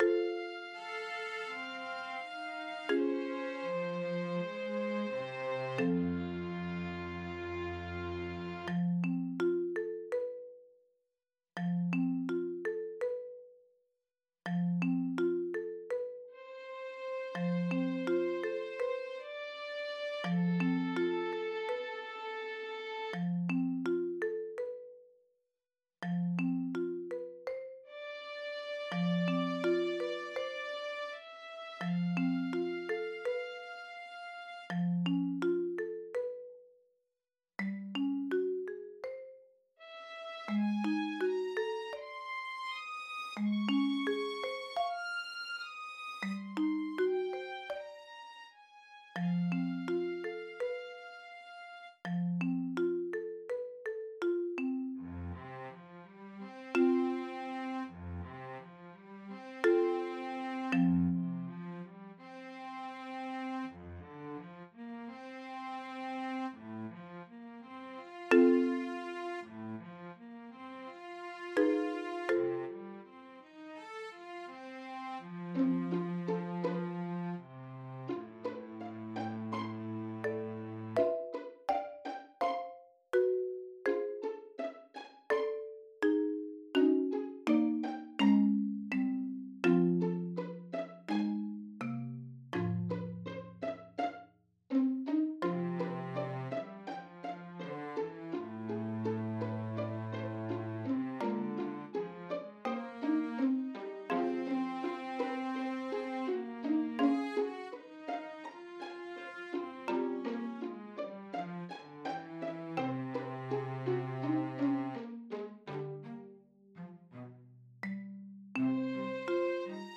processional
The classical ensemble was a trio: violin, cello, and marimba.
I don't have a live recording, but I have a decent version generated using MIDI instruments. Its main drawback is that the marimba uses only single strokes, when it should be sustained almost everywhere.